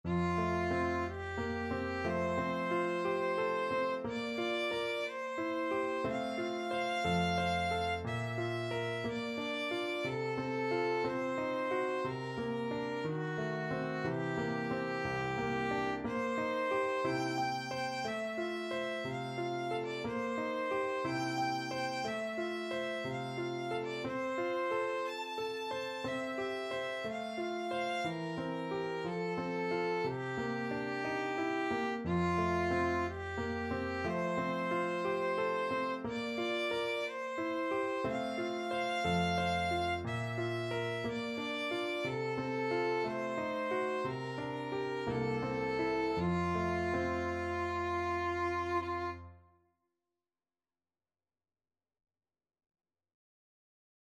Classical Granados, Enrique Dedicatoria (from Cuentos de la Juventud, Op.1) Violin version
F major (Sounding Pitch) (View more F major Music for Violin )
2/4 (View more 2/4 Music)
~ = 60 Andantino (View more music marked Andantino)
Classical (View more Classical Violin Music)
granados_dedicatoria_VLN.mp3